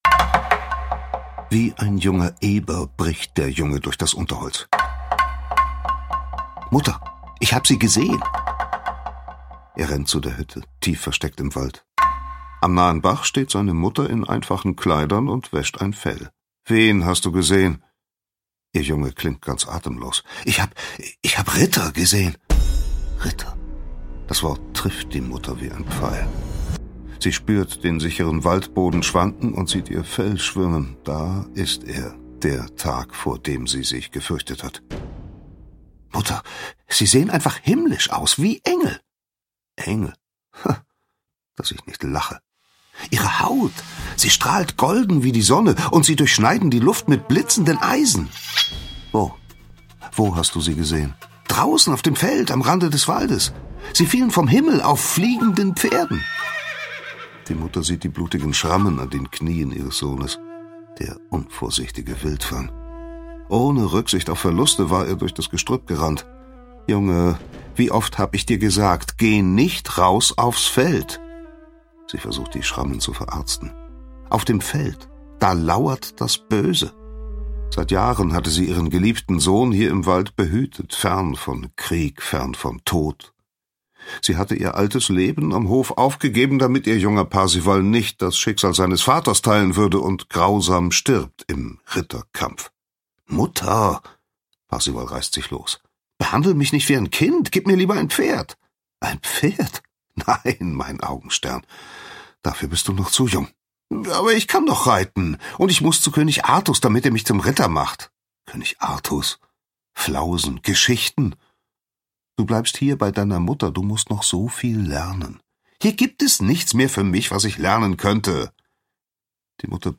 Schlagworte Geschichte • Götz von Berlichingen • Hörbuch; Lesung für Kinder/Jugendliche • Jeanne d'Arc • Kinder • Kinder/Jugendliche: Sachbuch: Geschichte • Krieger • Lancelot • Legenden • Mittelalter • Parzival • Richard Löwenherz • Ritter • Ritter; Kindersachbuch/Jugendsachbuch • Sachwissen • Wahrheit